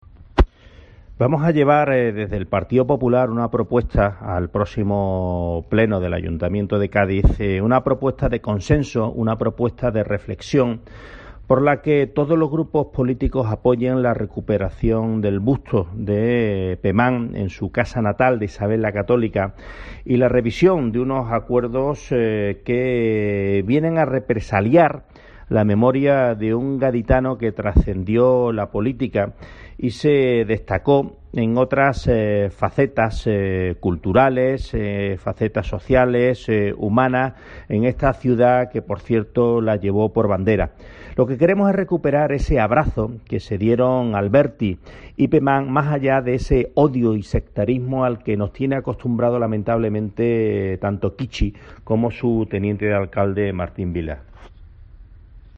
Juancho Ortíz, portavoz del PP, sobre el busto de Pemán